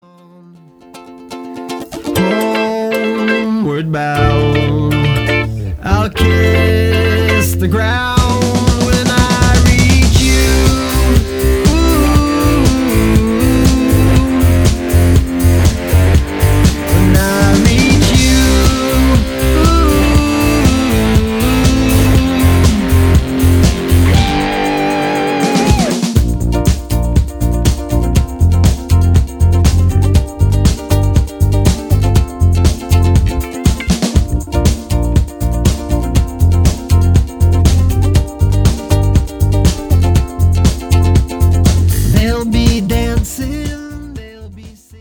Contemporary Jewish music with a rock/folk vibe.